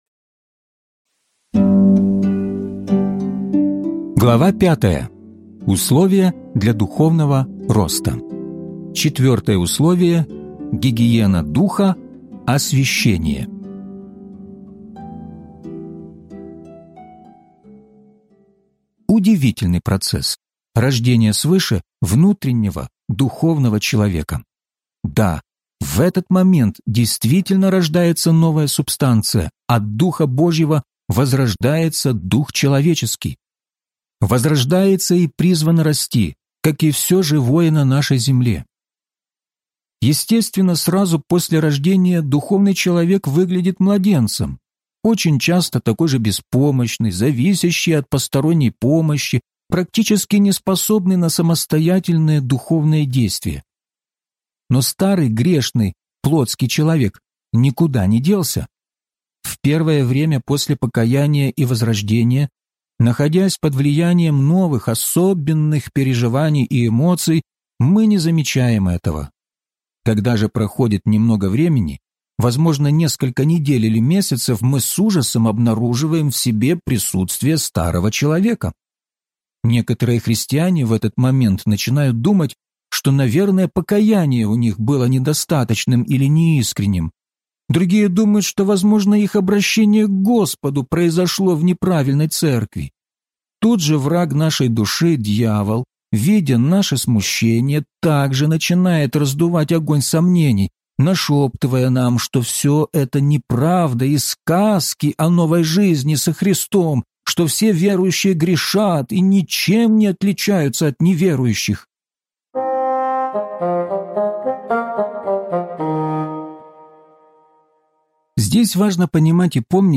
Возрастайте! (аудиокнига)